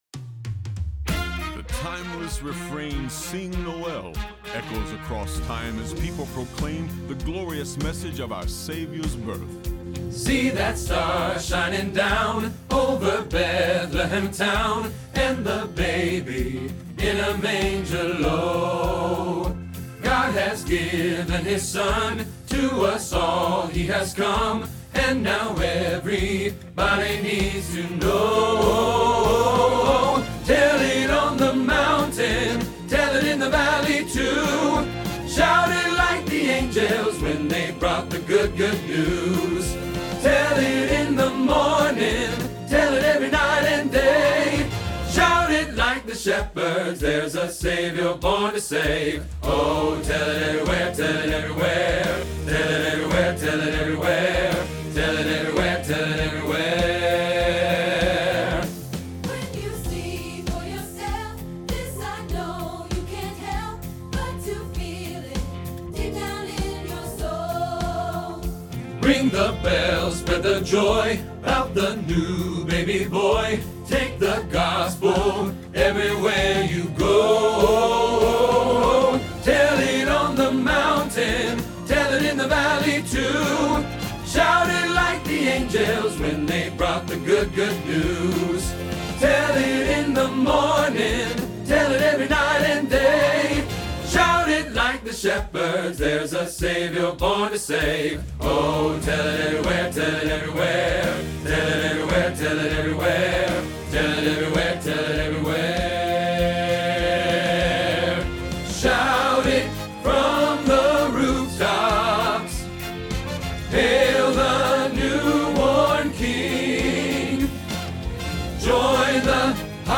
Tenor Practice Trax